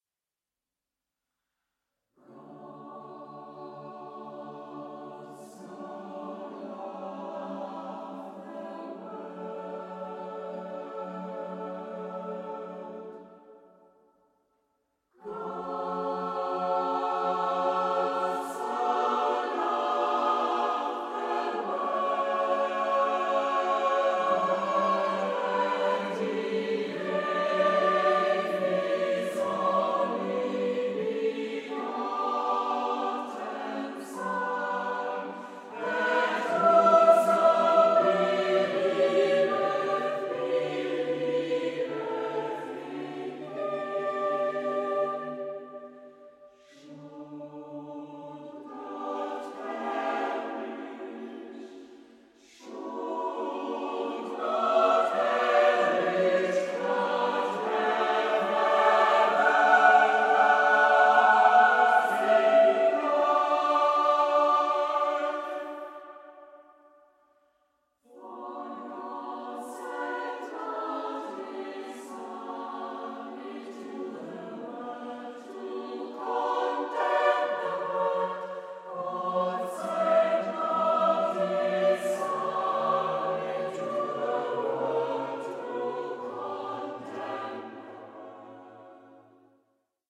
SATB (4 voices mixed) ; Full score.
Consultable under : Romantique Sacré Acappella
Sacred ; Partsong ; Spiritual ; Hymn (sacred) Mood of the piece: affectionate ; gentle ; andante
Tonality: D major